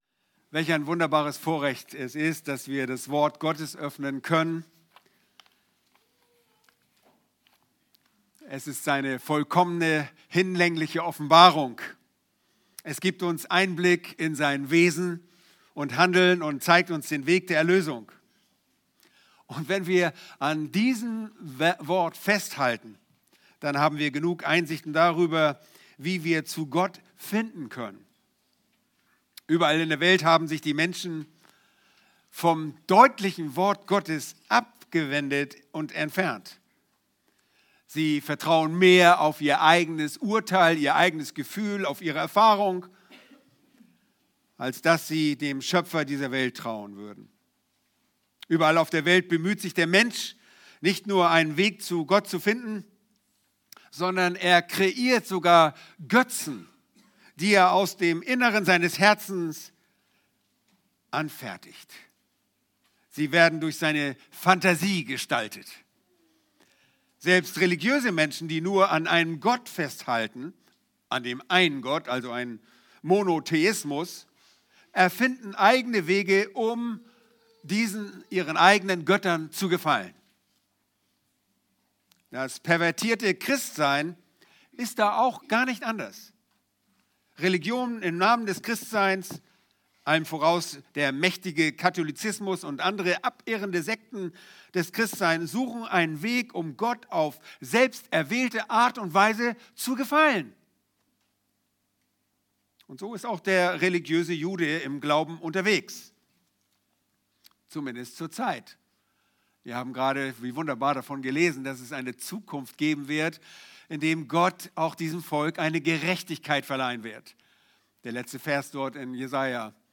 Predigten - Übersicht nach Serien - Bibelgemeinde Barnim